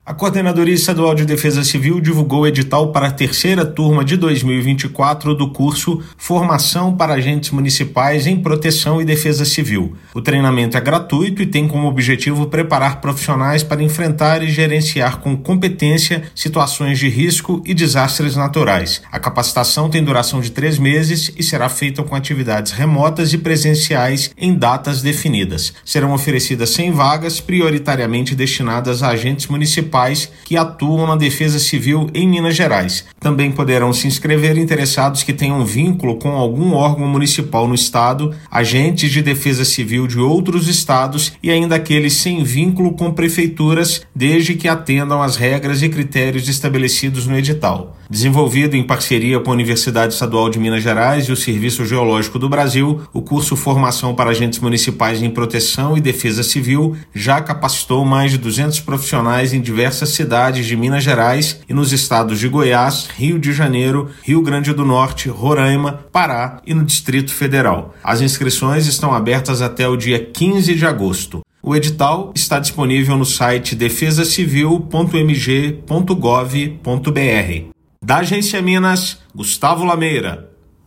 Treinamento é gratuito e busca oferecer conhecimentos essenciais para gerenciar situações de emergência e desastres naturais de forma eficiente e segura. Ouça matéria de rádio.